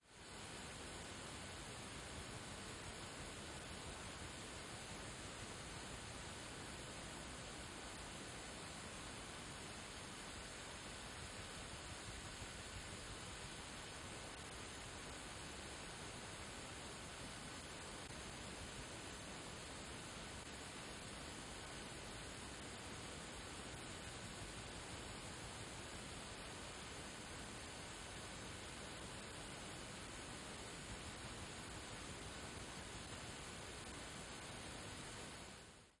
天气 " 金属窗台上的雨
描述：在这个样本中，您会听到强烈的雨水冲击房屋的侧面和金属窗台。一些雨滴也撞到了窗户的玻璃上。在背景中几乎听不到一些风和其他城市噪音。 使用Zoom H4n记录器的内置立体声麦克风记录样本。对它进行了轻微处理，以去除与实际记录无关的不需要的低频和高频。
标签： 雨滴 窗台 天气 窗户 玻璃 金属 下雨
声道立体声